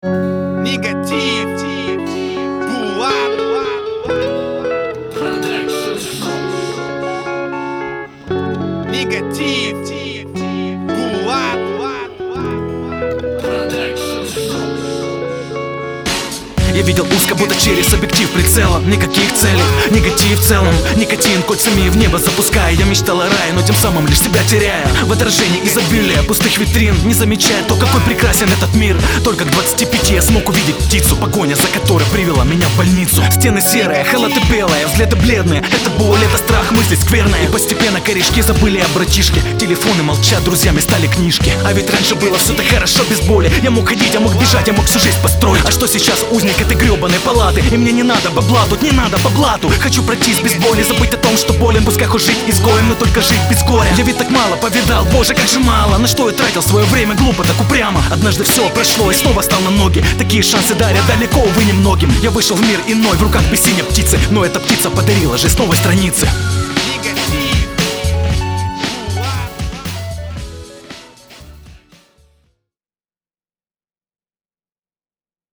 • Качество: 320, Stereo
русский рэп